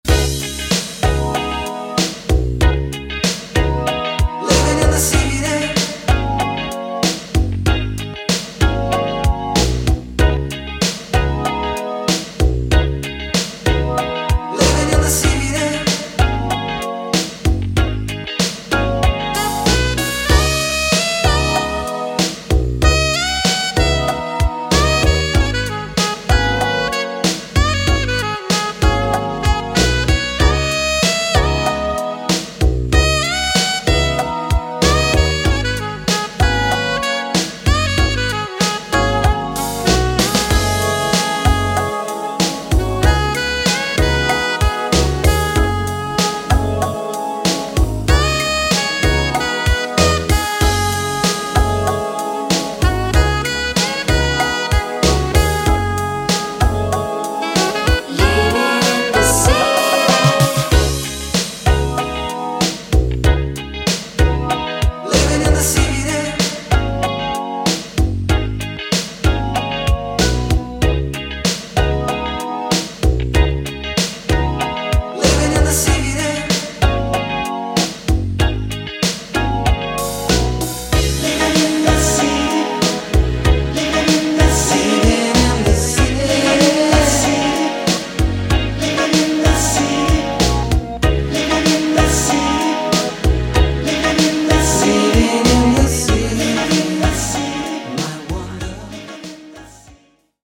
he presents a nostalgic vision of twilight synth-pop